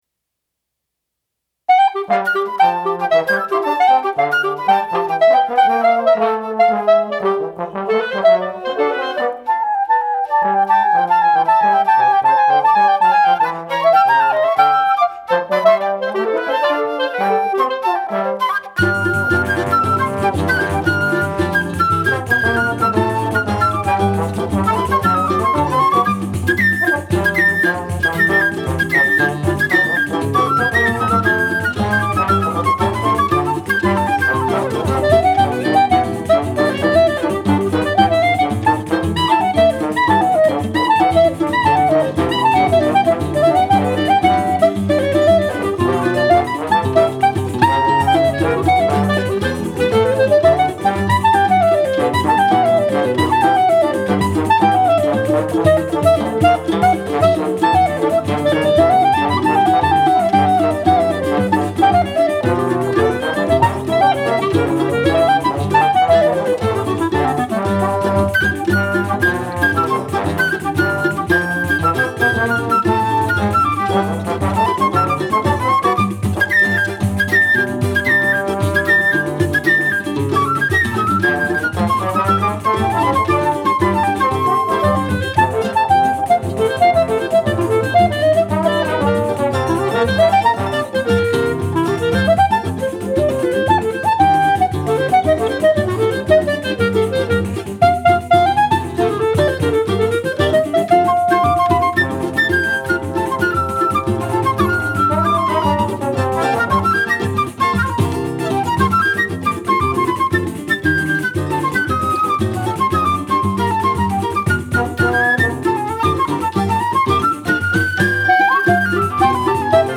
Prise de son live & Mixage
Choro